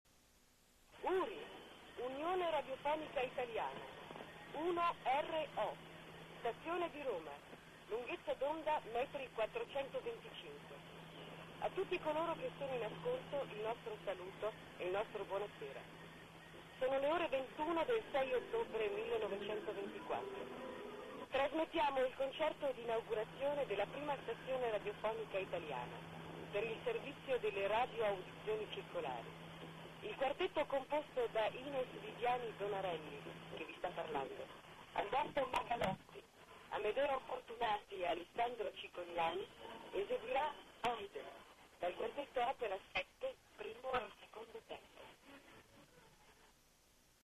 Prima emissione URI  unione radiofonica italiana